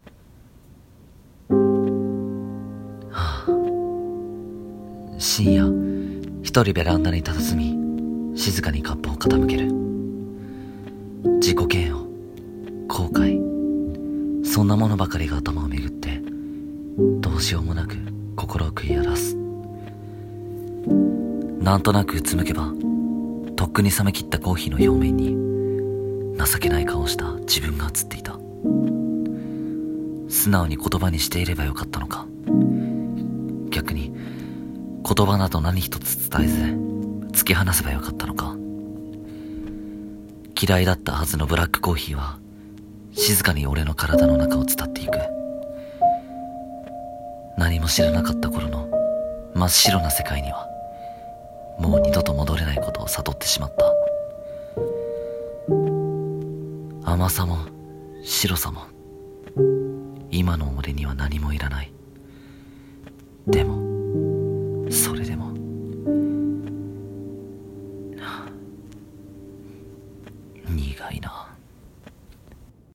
【一人声劇】どうしようもなく苦くて。